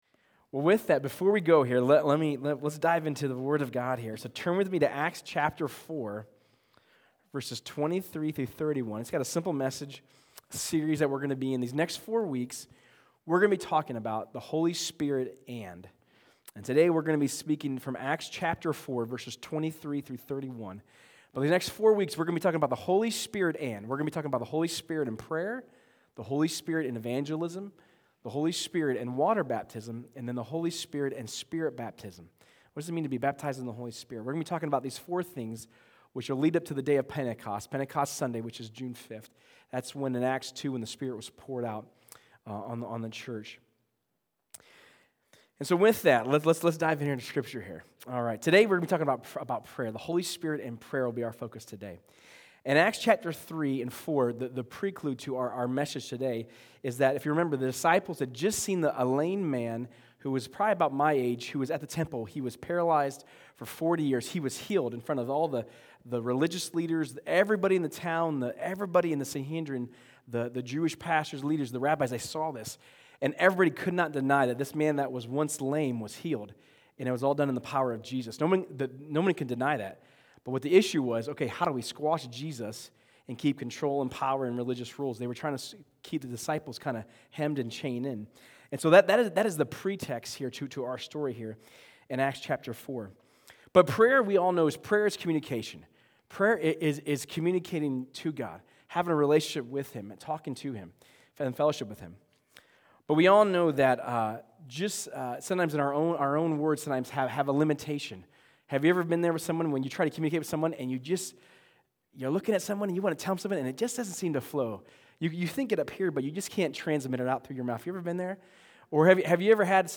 Sermons | Crosspointe Church